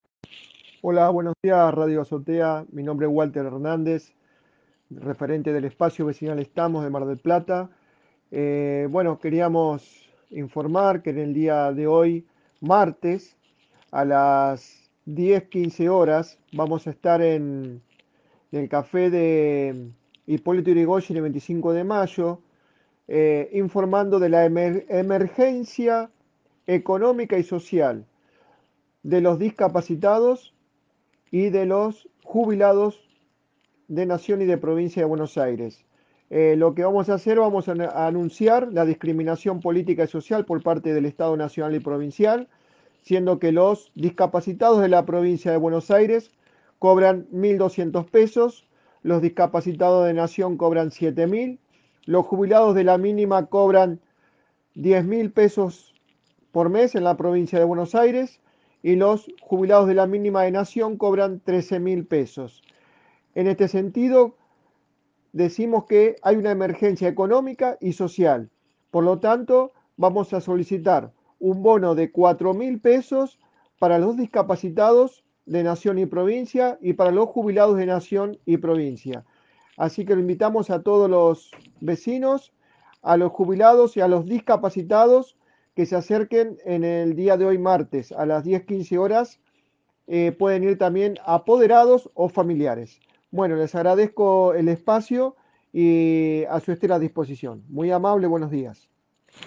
programa emitido de 7 a 9, por Radio de la Azotea